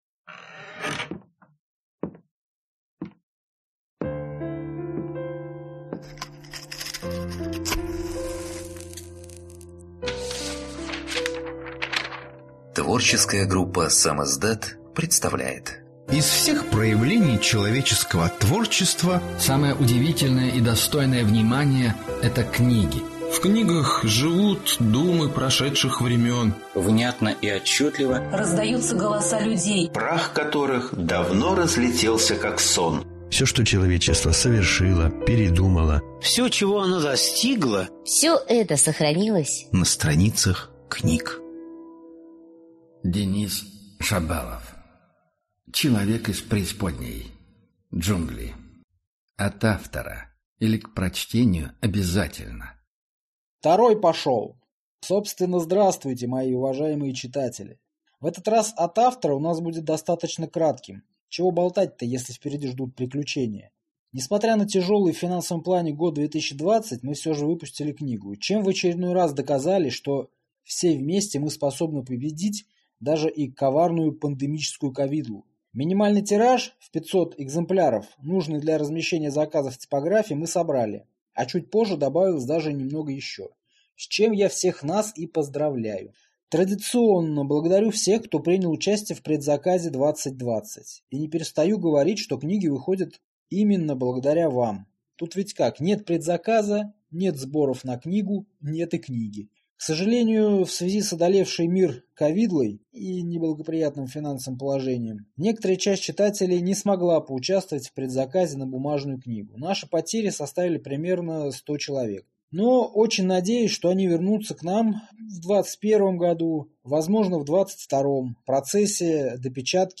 Аудиокнига Человек из преисподней. Джунгли | Библиотека аудиокниг